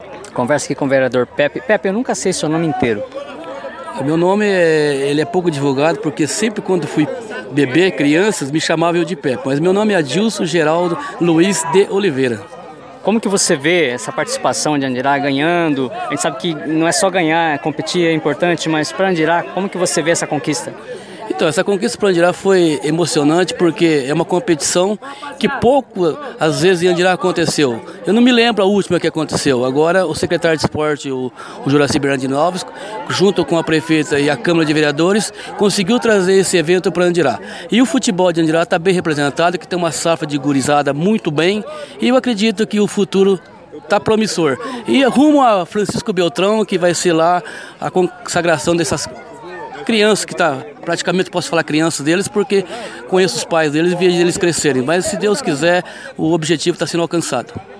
O vereador Adilson Geraldo Luiz de Oliveira, o popular Pepe, que assistiu à partida e participou da entrega das medalhas, disse que os atletas e equipe técnica estão de parabéns, pois foi esta foi uma conquista inédita: